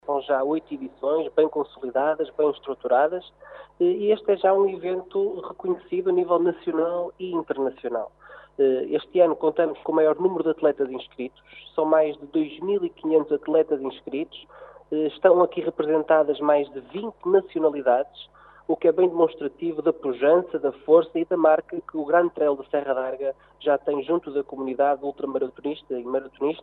A 8ª edição deste evento desportivo, que é já uma referencia a nível nacional e internacional, conta com mais de 2.500 atletas inscritos como avançou à rádio caminha o vereador Rui Lages, responsável pelo pelouro do desporto da Câmara de Caminha.